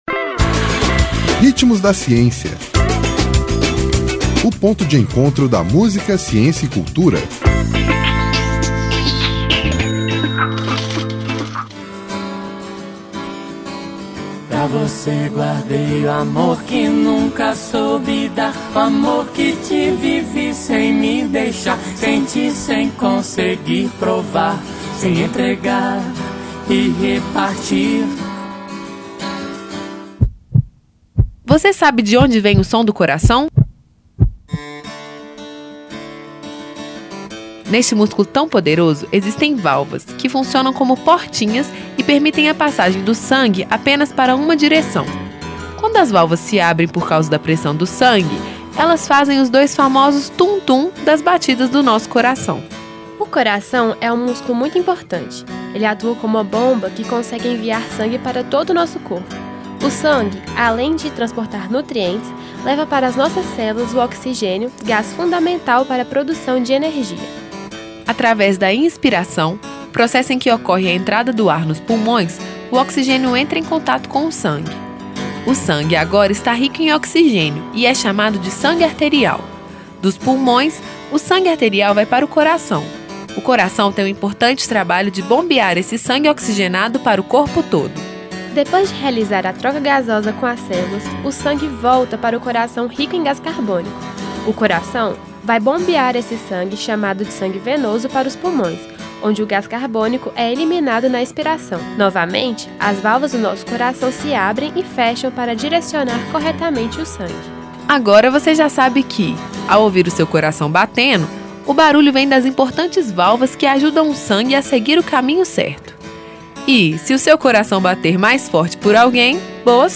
Intérprete: Ana Cañas e Nando Reis